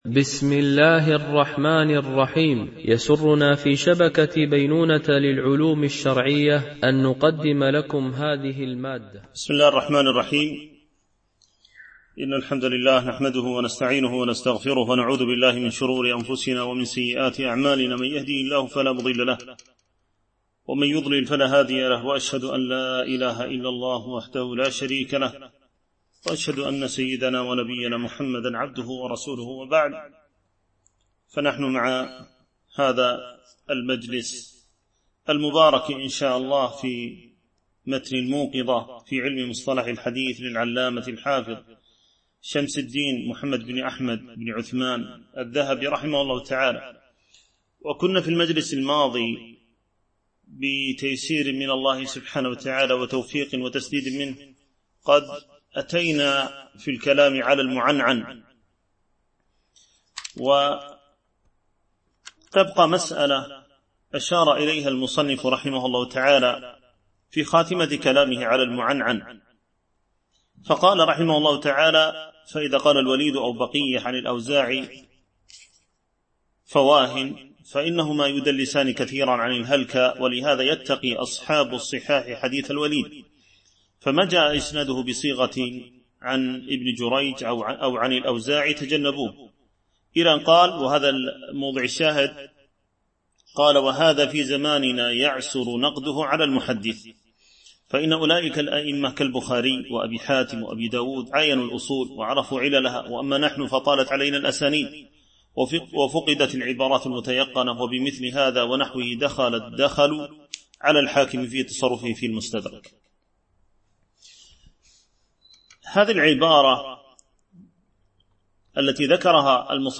شرح الموقظة في علم مصطلح الحديث ـ الدرس 10 (الحديث المدلس والمضطرب)